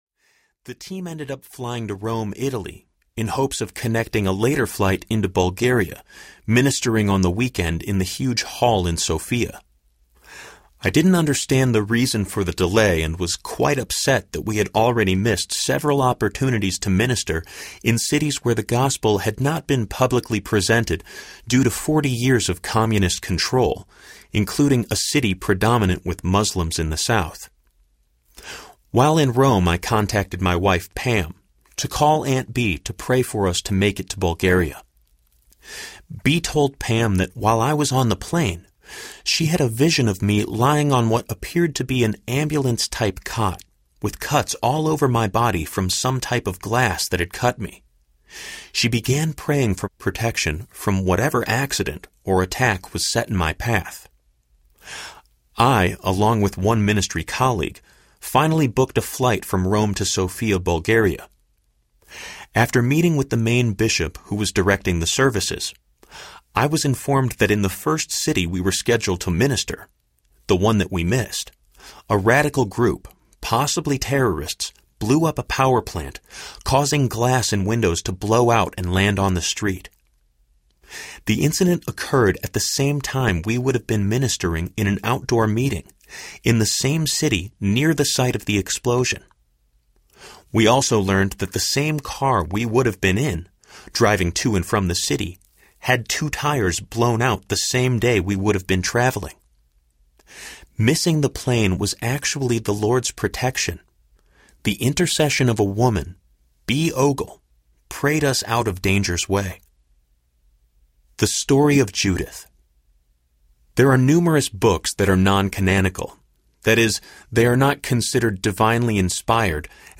Scarlet Threads Audiobook
Narrator
8.5 Hrs. – Unabridged